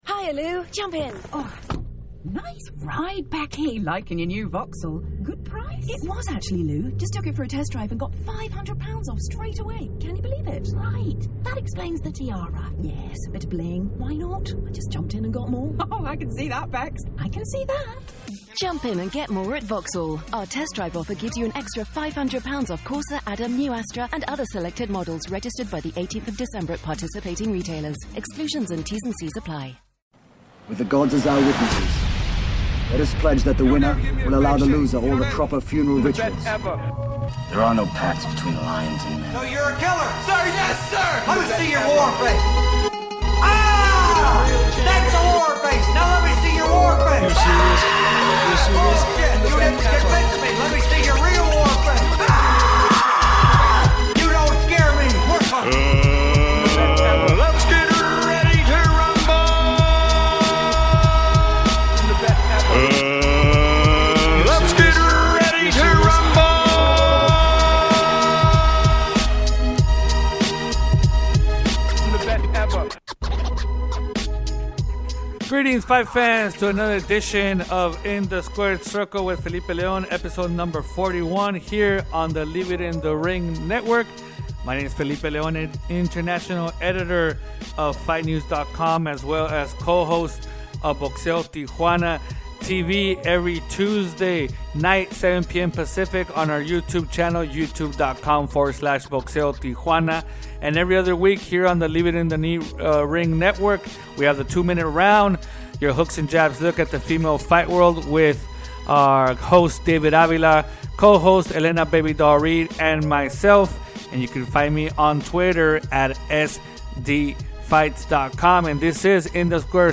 with a fast pace style of 30 minutes or less